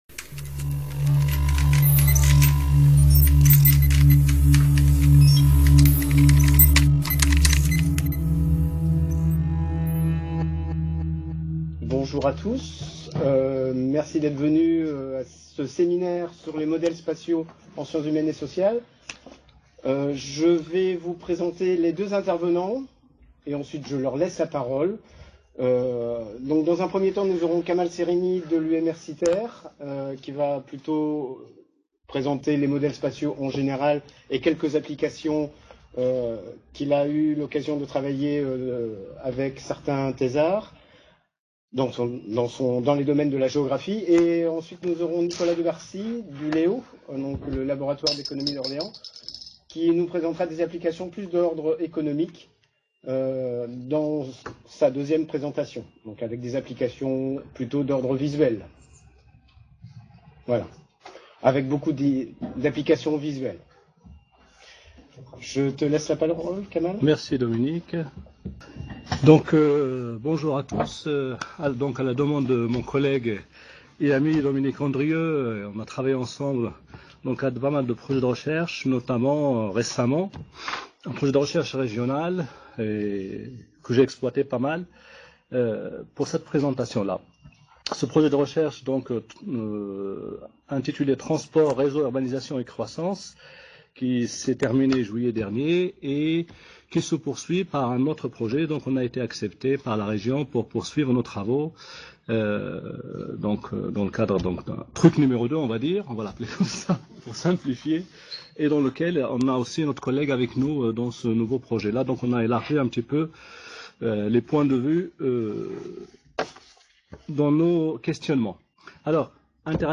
Le plus connu d’entre eux, la gravitation de Newton, est par exemple couramment employé en géographie. Ce séminaire présente : quelques applications de ces outils des sciences dites « dures » transposées en sciences humaines et sociales. En géographie, la distance entre des individus est un facteur important de la modélisation.